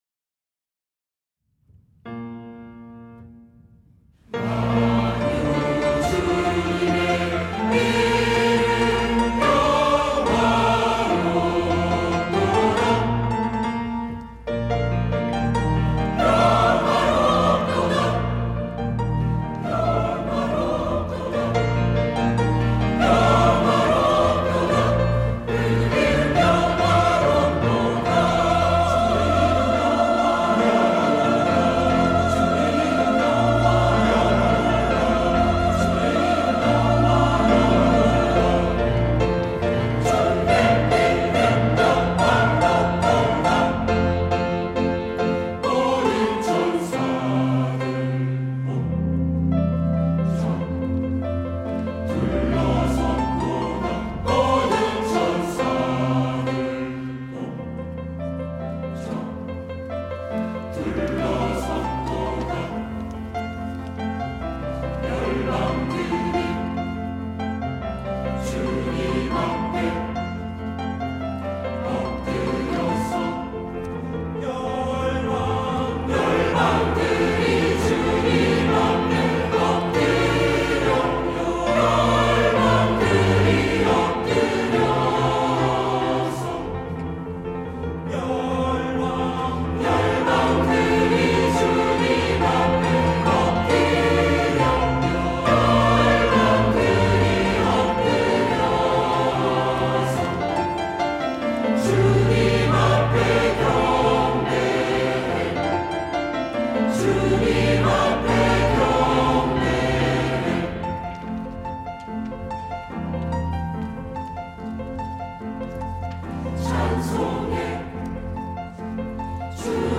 시온(주일1부) - 영화롭도다
찬양대